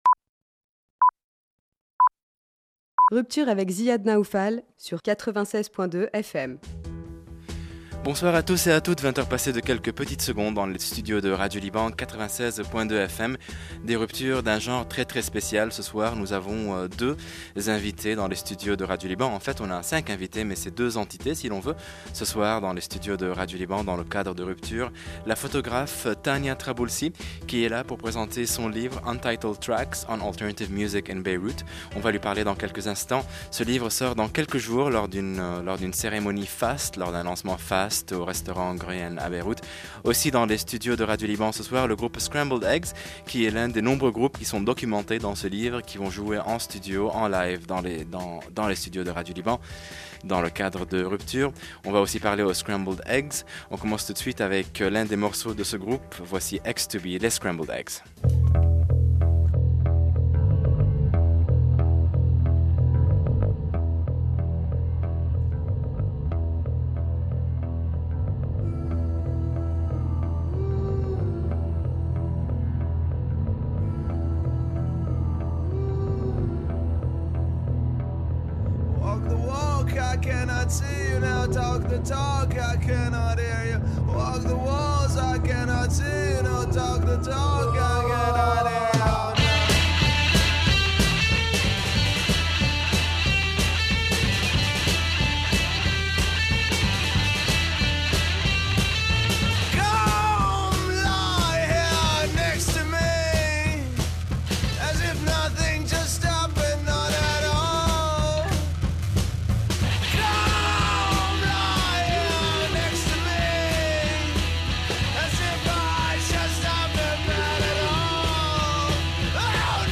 SCRAMBLED EGGS // 2nd Ruptured Session
Interview
As for Scrambled Eggs, they were the guests of ‘Ruptures’ that night to highlight their contribution to both the launch event for the book, and the ‘Prelude to Irtijal‘ mini-festival taking place on March 10-11.